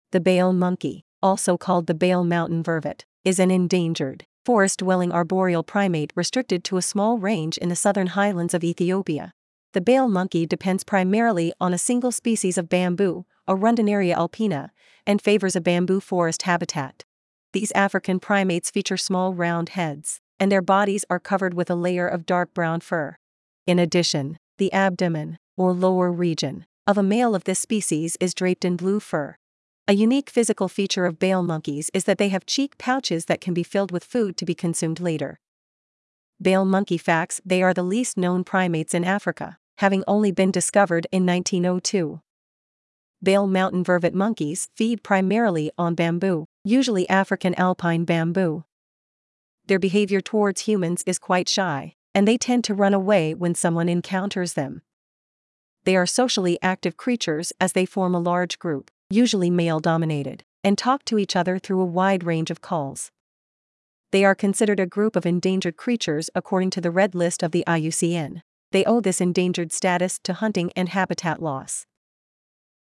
• They are socially active creatures as they form a large group, usually male-dominated, and talk to each other through various calls.
Bale-Monkey.mp3